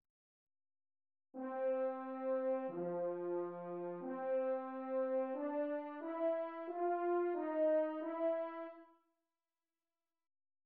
Nello sviluppo, Bruckner costruisce una seconda fuga, utilizzando come soggetto il tema del corale: